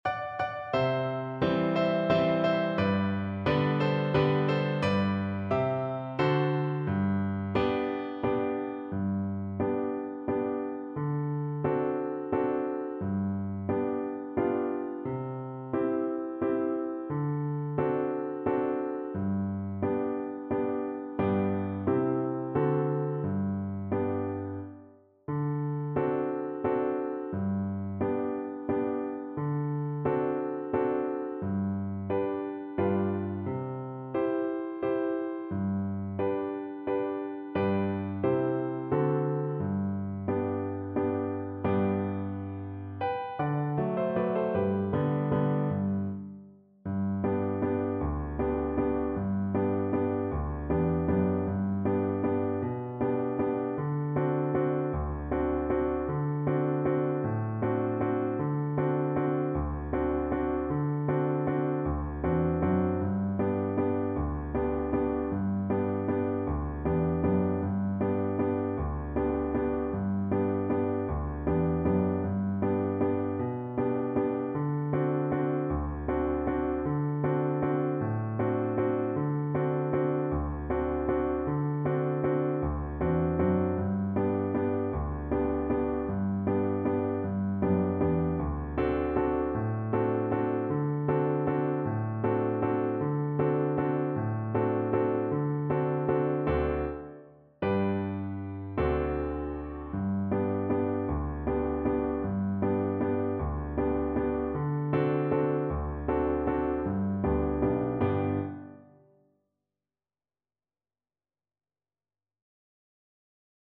3/4 (View more 3/4 Music)
Slow =c.88